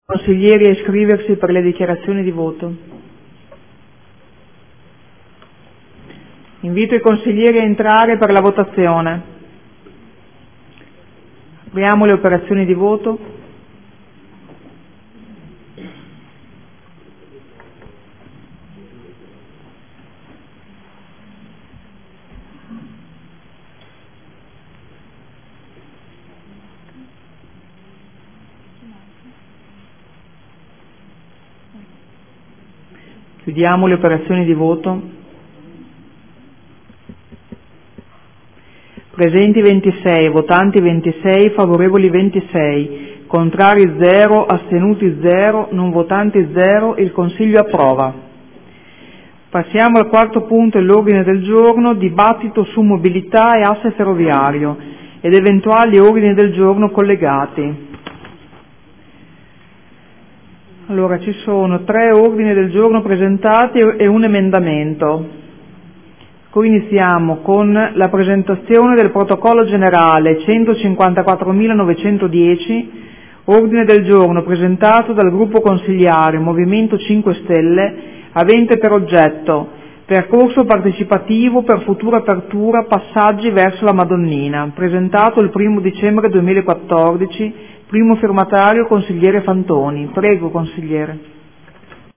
Seduta del 11/12/2014 Mette ai voto. Regolamento in materia di cremazione, dispersione e conservazione delle ceneri – Approvata.